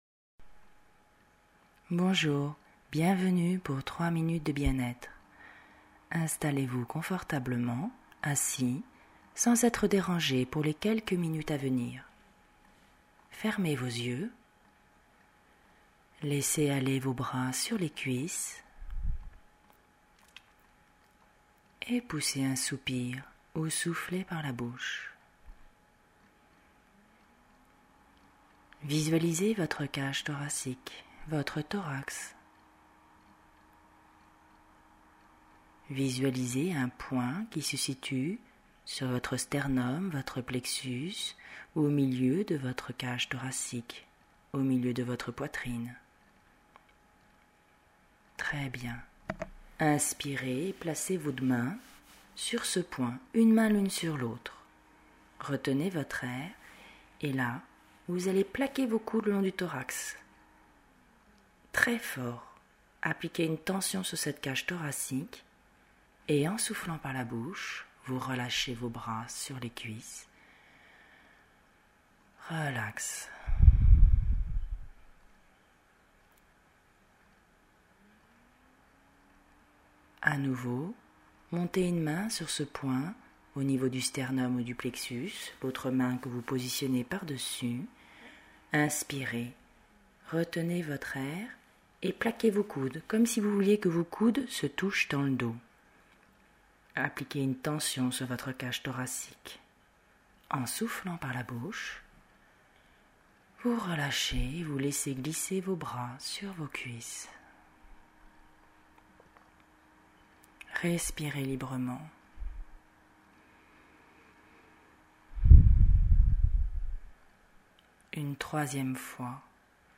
Genre : sophro